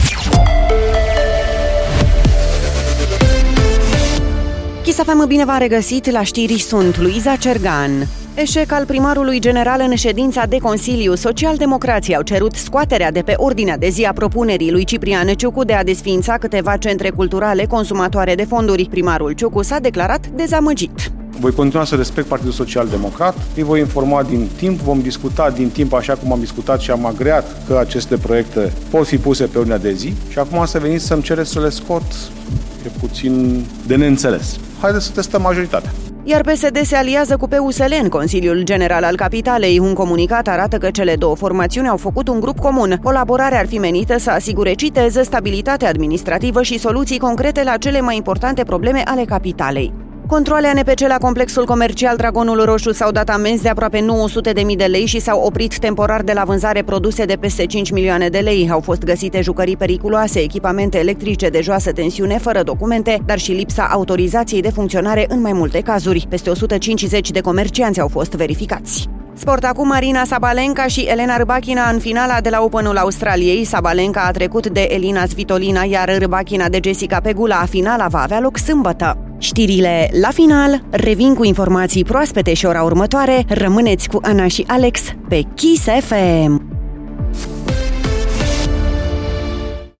Știrile zilei de la Kiss FM - Știrile zilei de la Kiss FM